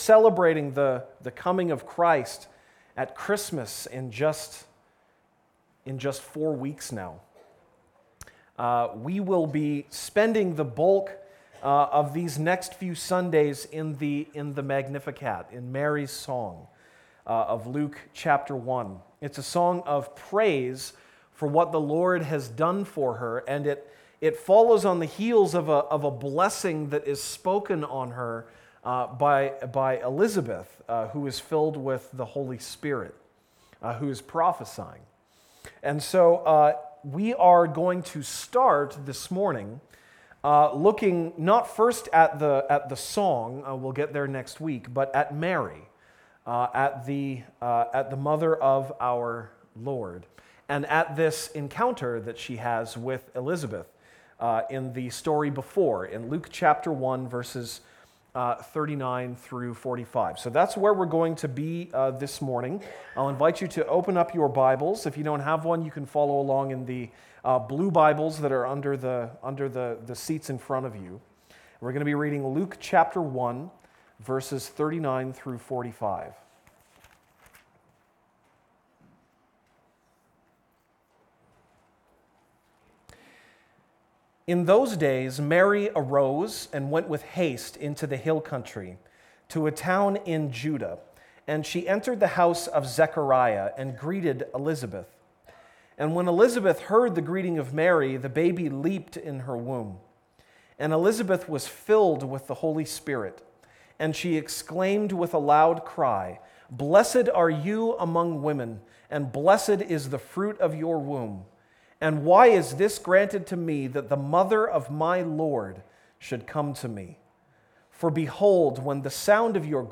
November 27, 2016 (Sunday Morning)